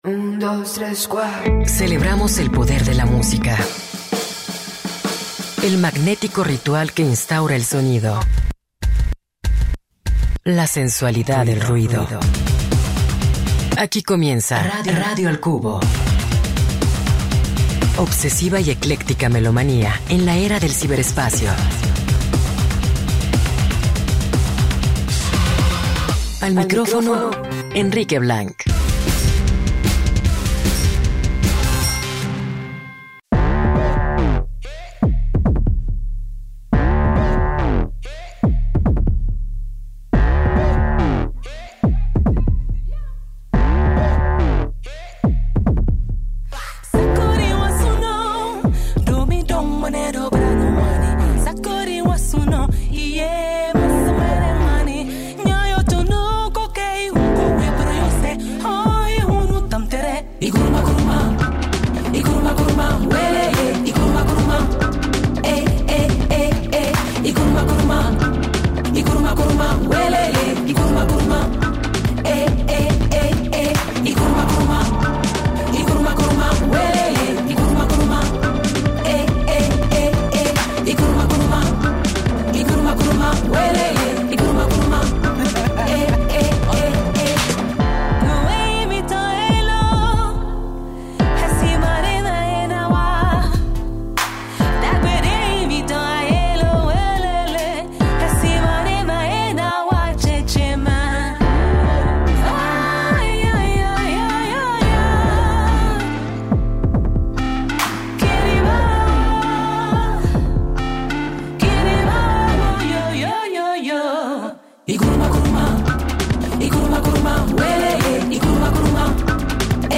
El día de hoy, tiene una mezcla de música muy interesante Escucha el 104.3FM